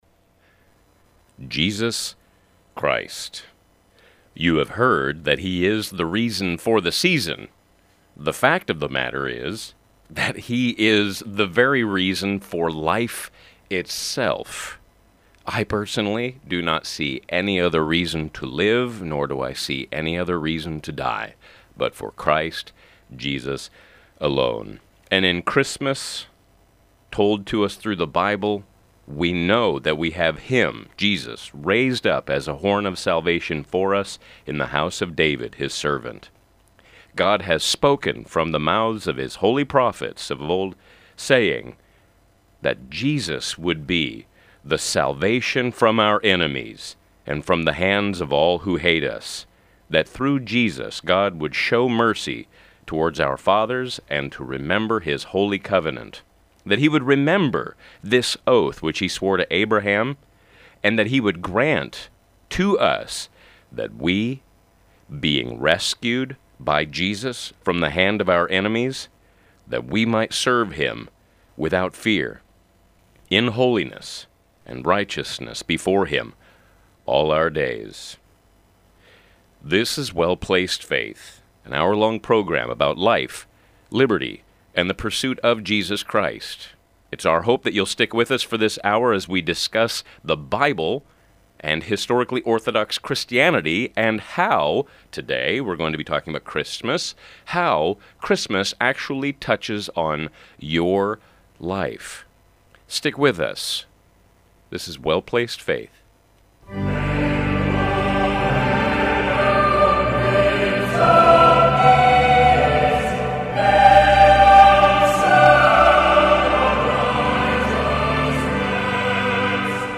“call in” program